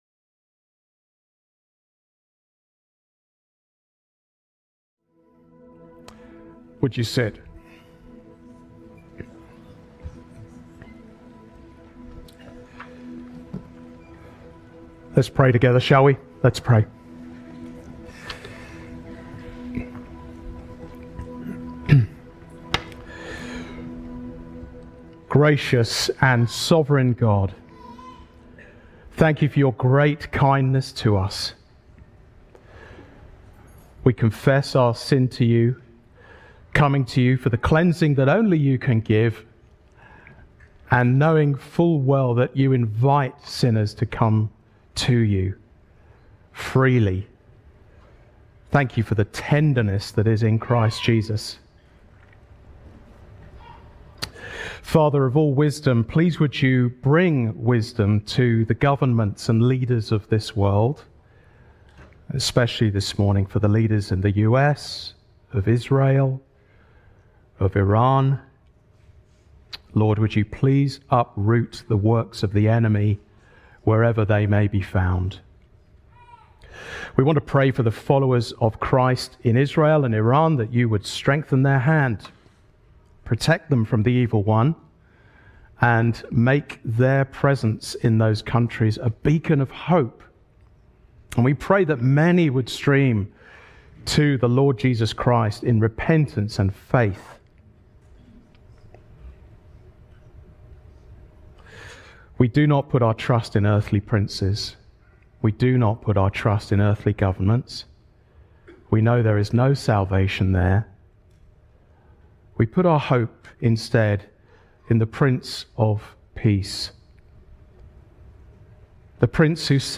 Sermons Christ Community Church: Daytona Beach, FL